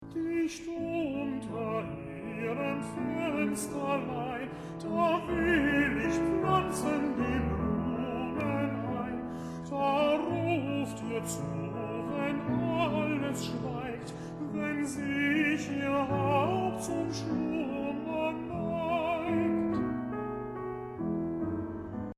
All-in-one melody/harmony this week.
This phrase features a modulation to (or a strong tonicization of) of a closely related key.
Both the melody and the bass line include some “essential” (i.e. harmonized) chromaticism.